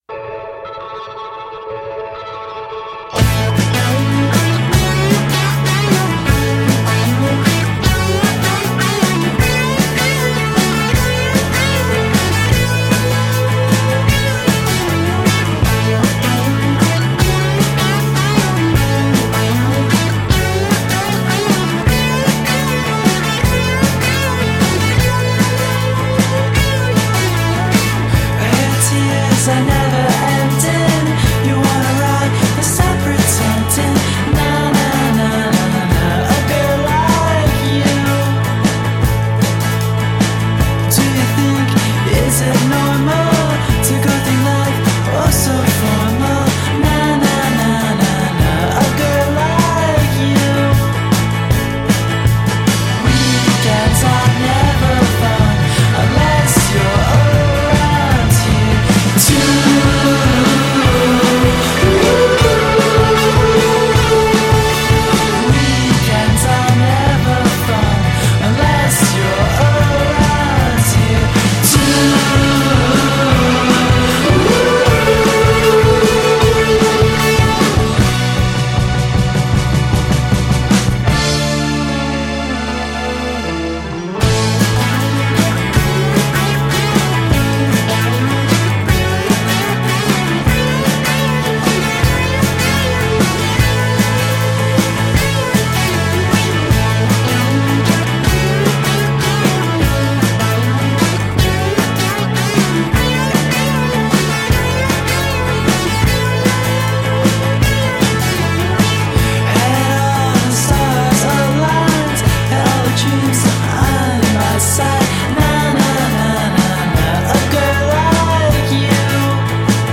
better studio production